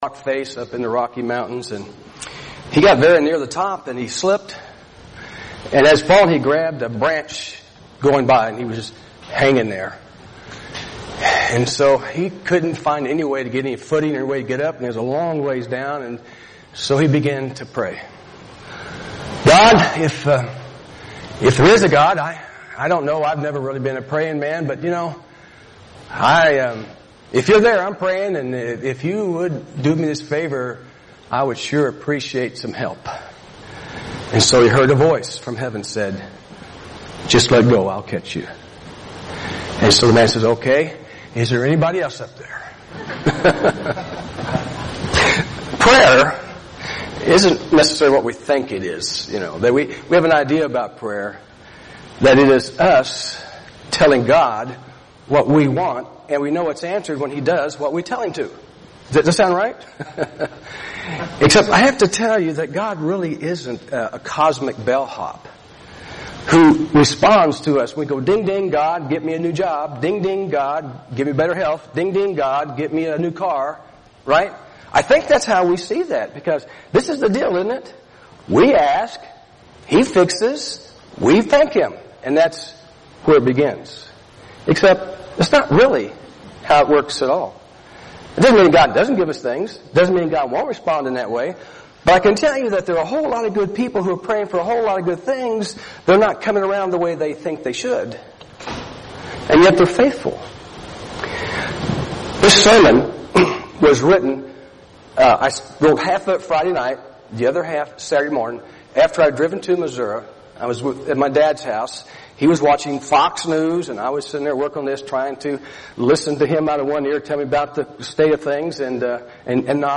preaches a message titled, “Praying God’s Will.”